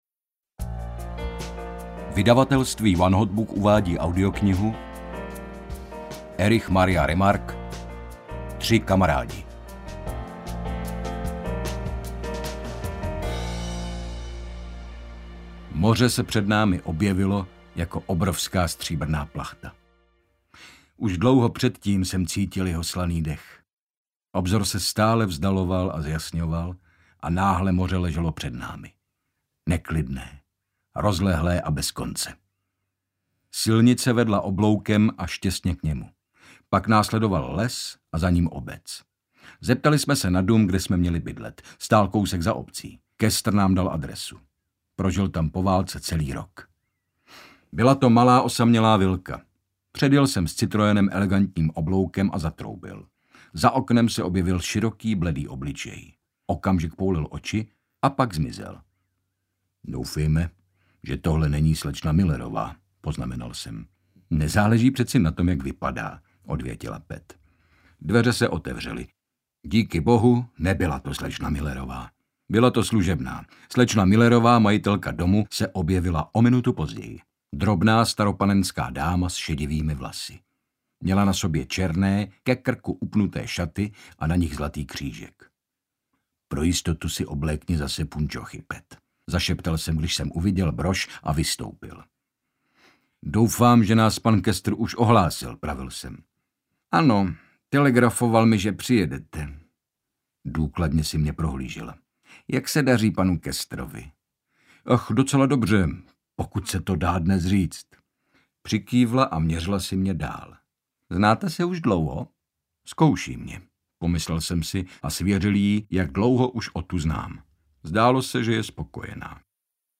Ukázka z knihy
• InterpretHynek Čermák